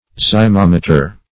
Search Result for " zymometer" : The Collaborative International Dictionary of English v.0.48: Zymometer \Zy*mom"e*ter\, Zymosimeter \Zy`mo*sim"e*ter\, n. [Gr.
zymometer.mp3